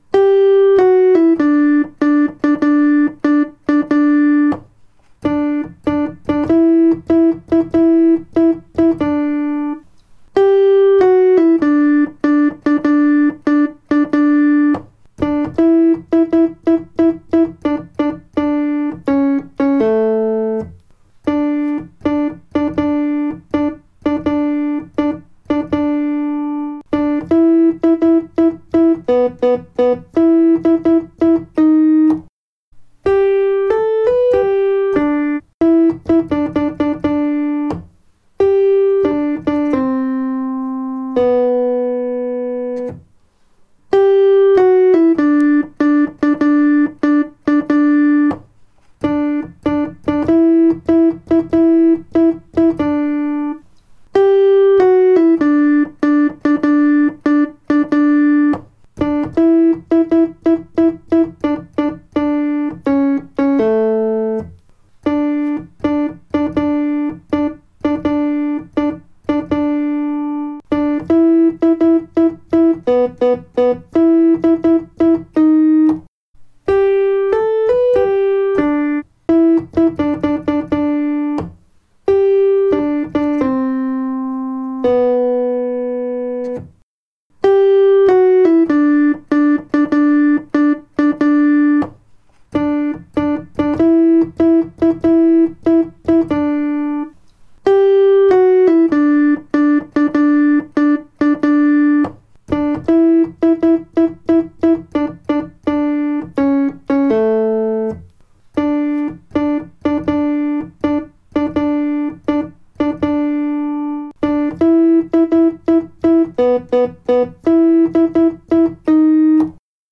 dkfolk_alt3.mp3